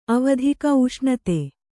♪ avadhika uṣṇate